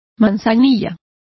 Complete with pronunciation of the translation of camomile.